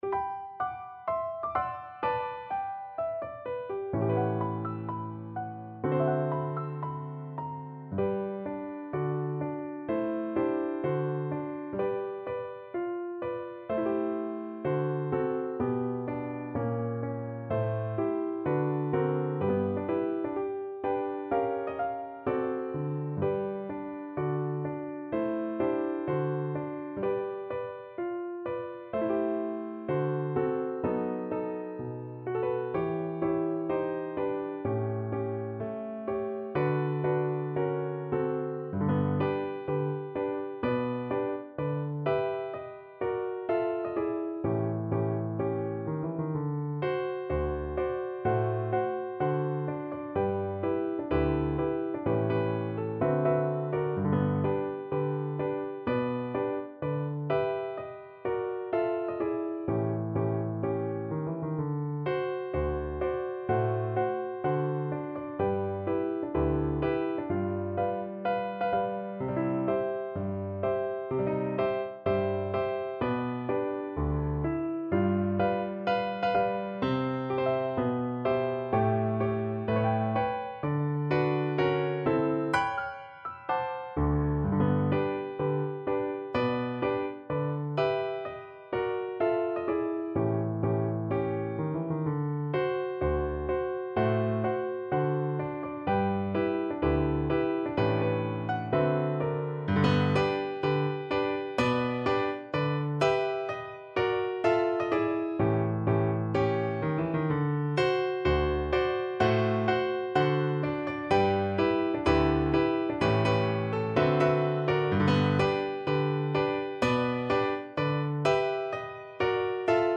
Moderato cantabile =126
Pop (View more Pop Alto Recorder Music)